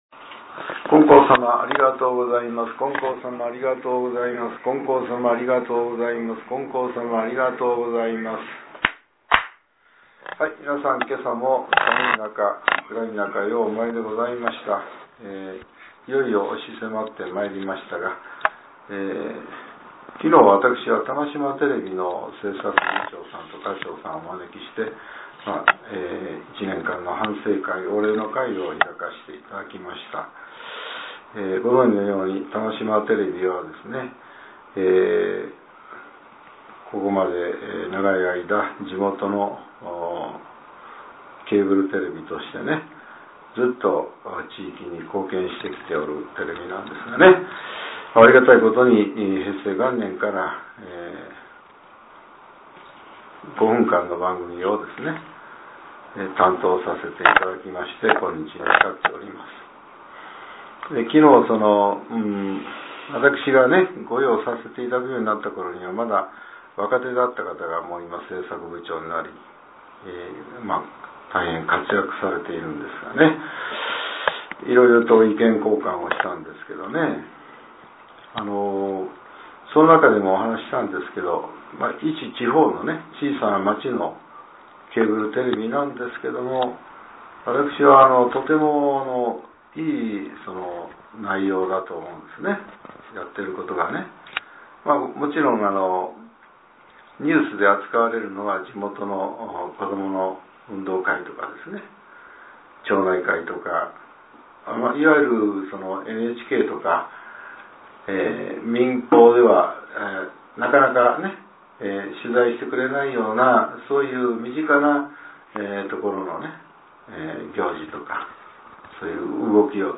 令和６年１２月２７日（朝）のお話が、音声ブログとして更新されています。